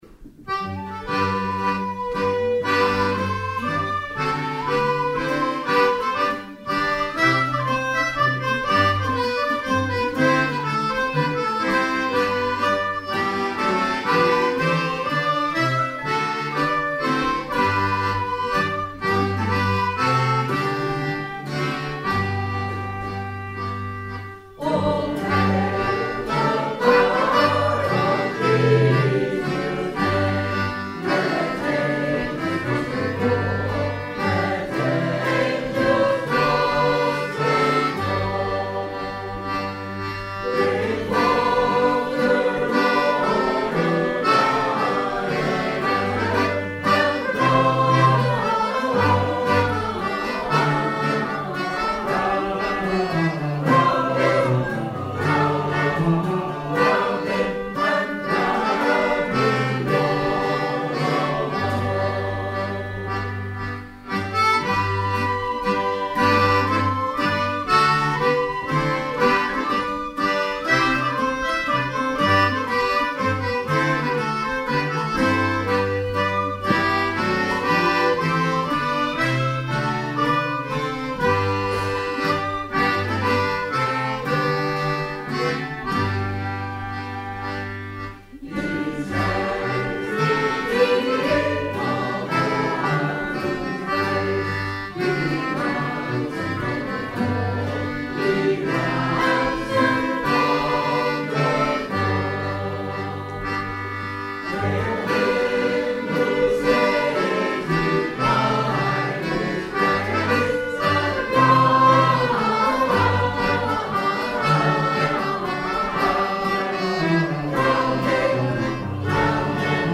11 December 2024 : Carols at the Institute
This year, to make up for not going out and singing on the streets, we met for a general sing on the morning of 11 December, at the Institute, including instruments and members of the recently-formed Sacred Harp Singing group.
Instruments: fiddle, octave mandolin, whistle, recorder, concertinas, cello.